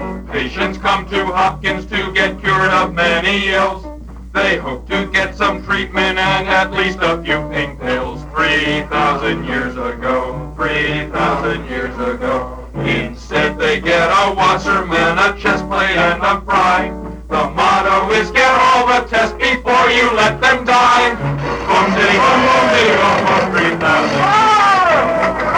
Be warned that this is not a professional quality recording,